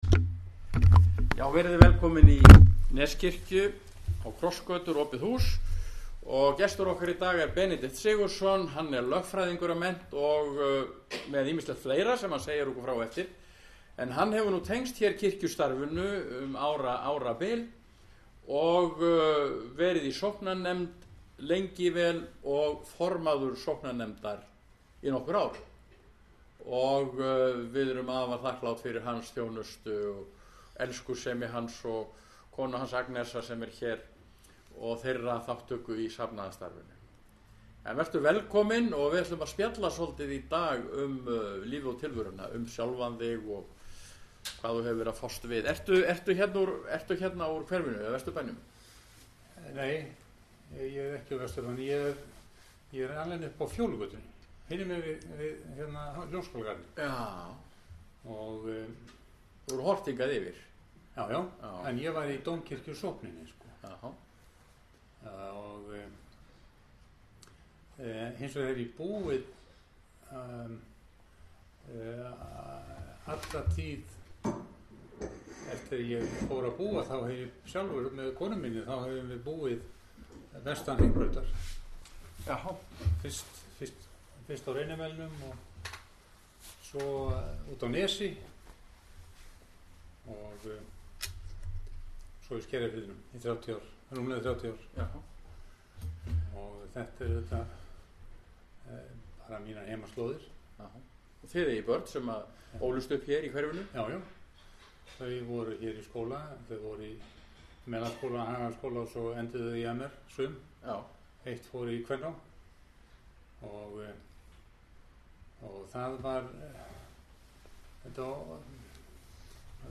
Krossgötur – Opið hús er dagskrá sem fram fer í Neskikju á miðvikudögum yfir vetrartímann.